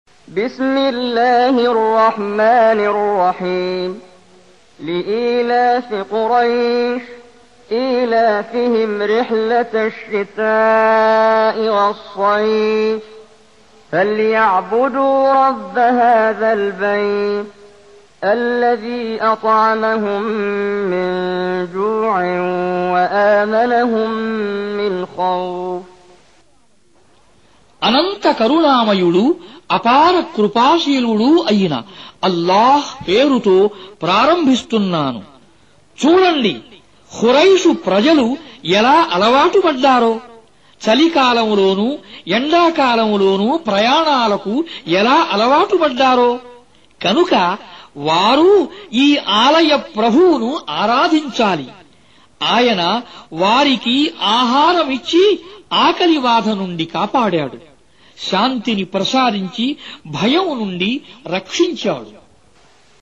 Surah Repeating تكرار السورة Download Surah حمّل السورة Reciting Mutarjamah Translation Audio for 106. Surah Quraish سورة قريش N.B *Surah Includes Al-Basmalah Reciters Sequents تتابع التلاوات Reciters Repeats تكرار التلاوات